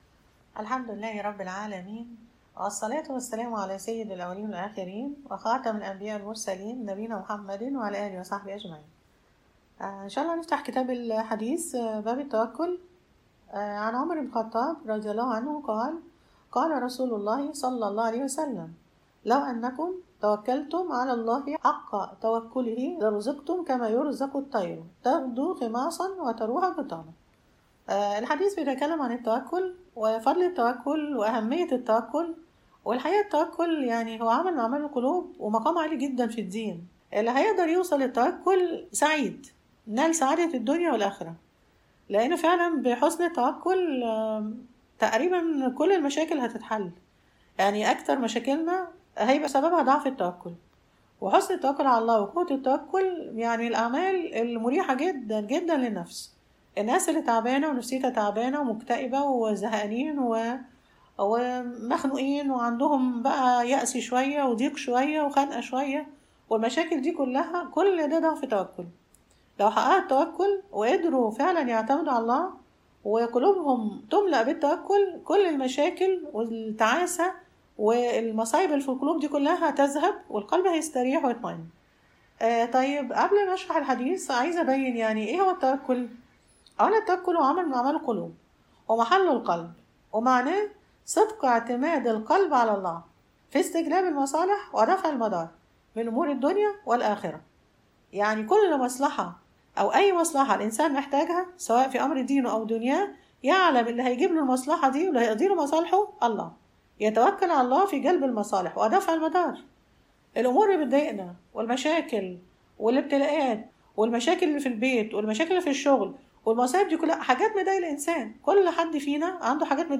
المحاضرة الثامنة_ “التوكل”